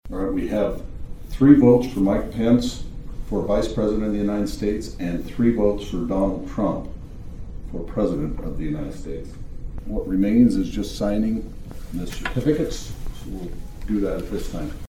As determined by the popular vote in the state, Rhoden announced the results.